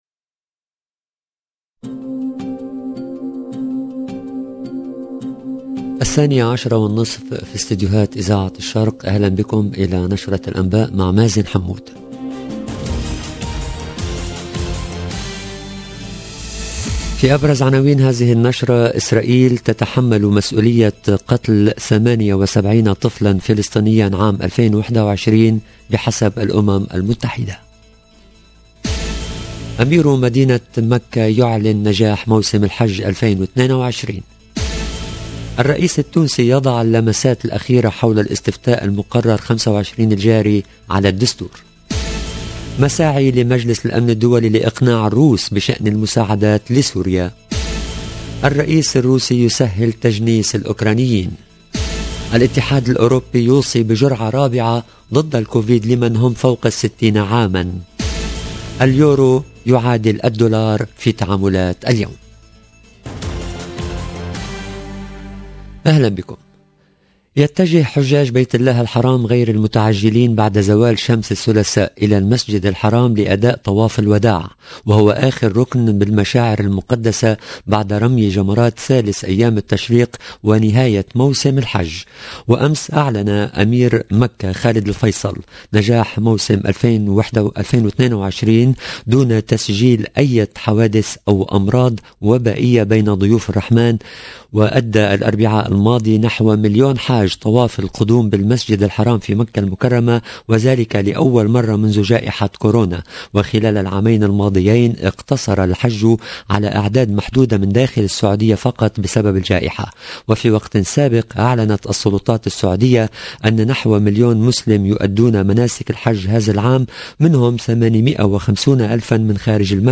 LE JOURNAL EN LANGUE ARABE DE MIDI 30 DU 12/07/22